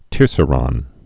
(tîrsə-rŏn)